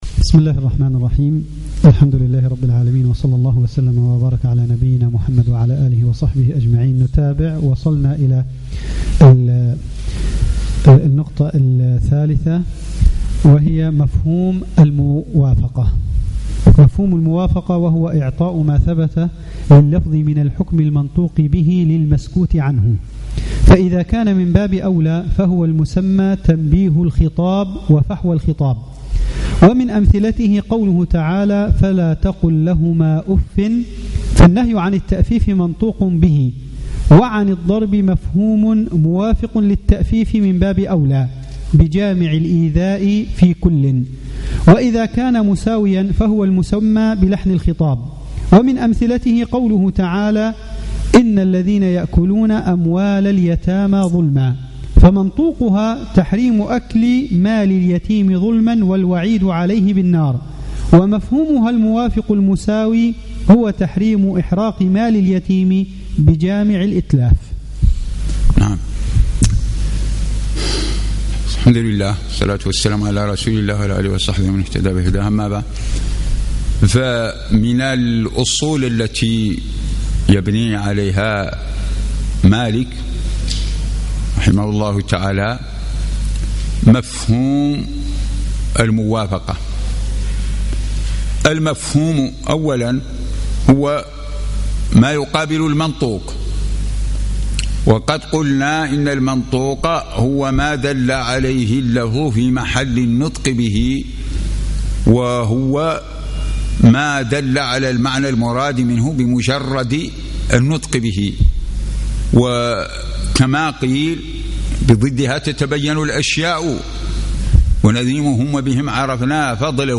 صباح الثلاثاء 21 جمادى الأولى 1437 الموافق 1 3 2016 بمبني تدريب الأئمة والمؤذنين
الدرس الرابع